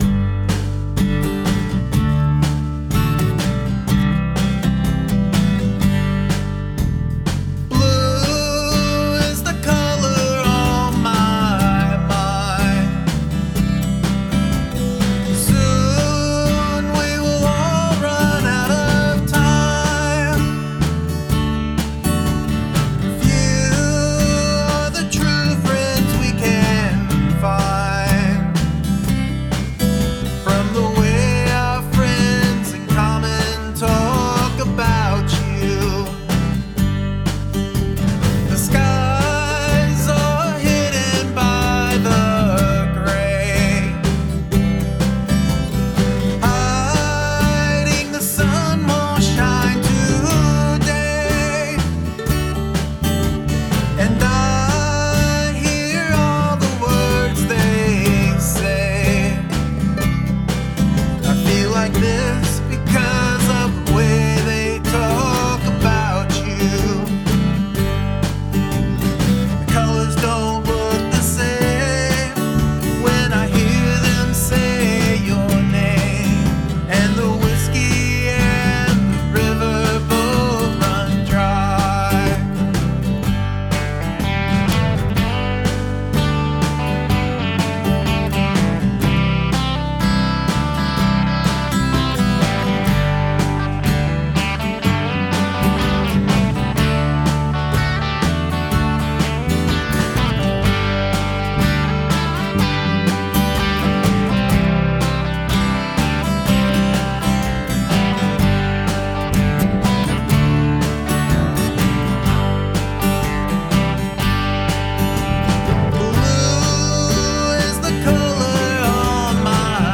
The mandolin is a great touch.